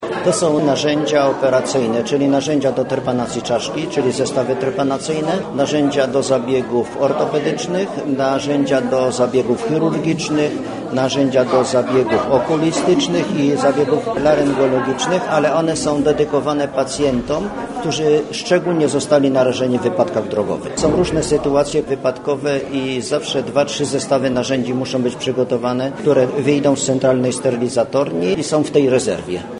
O zapotrzebowaniu na takie specjalistyczne wyposażenie nowego bloku operacyjnego mówił podczas podpisania dokumentów o dofinansowaniu